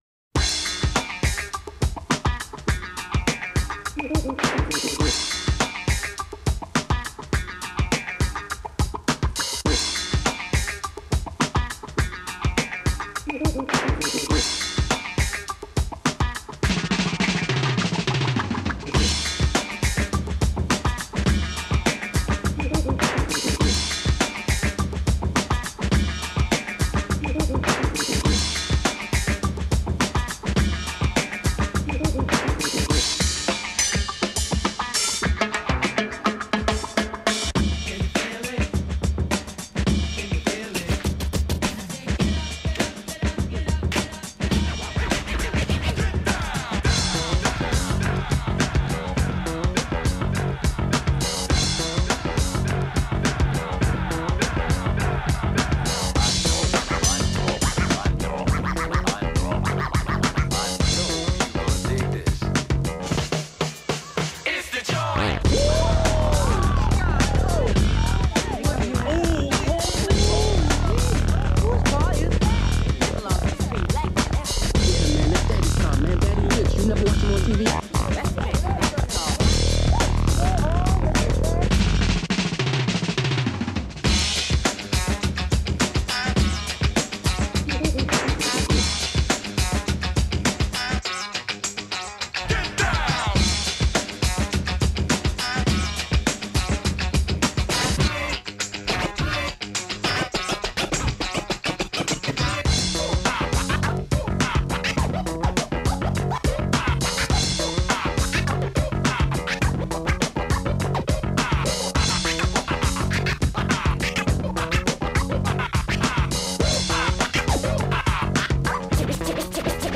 as rehashed instrumentals of the record’s a-side
party-jams